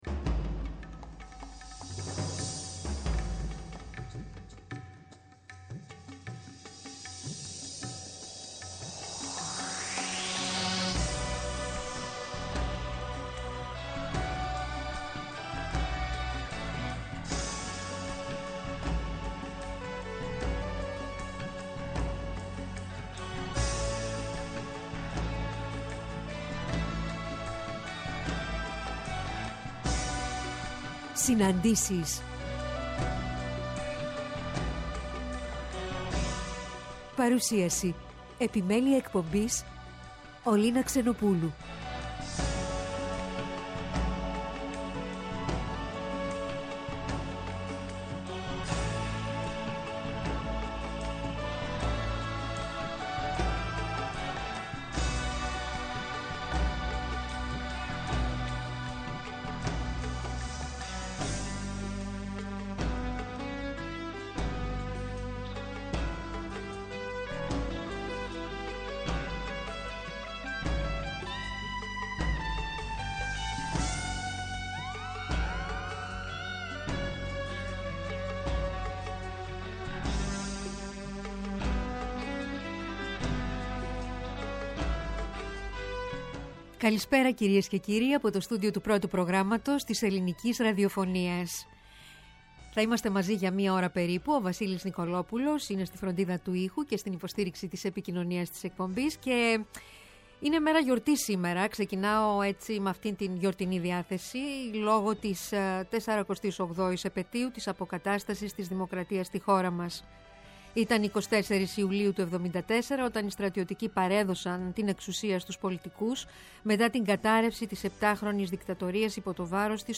Αποκαλυπτικές Συναντήσεις με πρόσωπα της κοινωνικής, πολιτικής και πολιτιστικής τρέχουσας επικαιρότητας με τη μουσική να συνοδεύει, εκφράζοντας το «ανείπωτο».